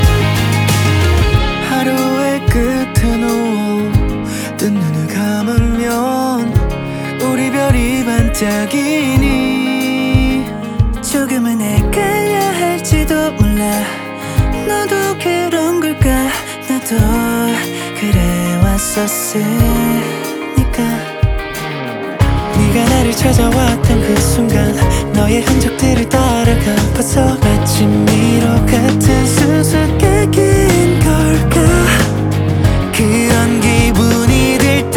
Жанр: Рок
Korean Rock